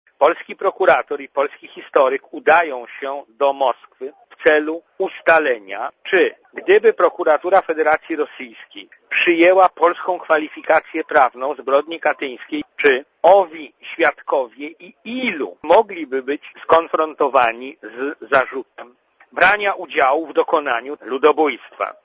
Prof. Kulesza z IPN dla Radia Zet